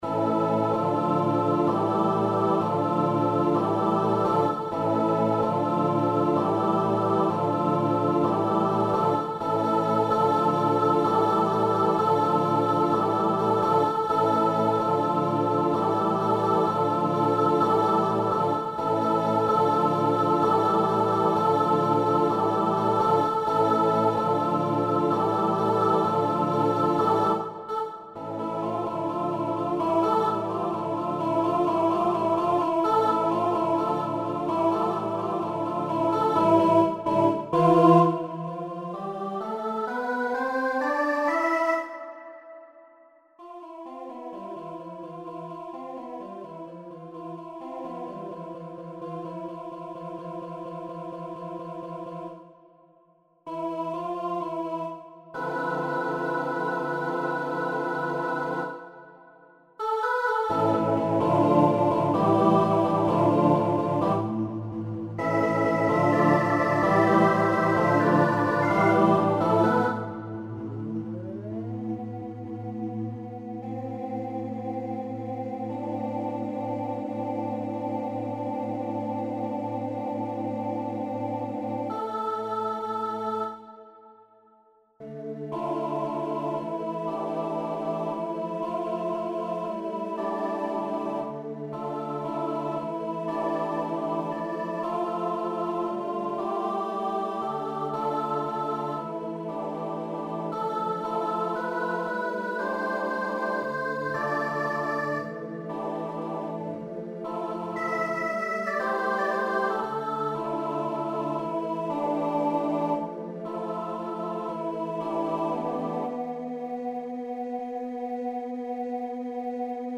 This challenging 8-part work for mixed voices
SSAATTBB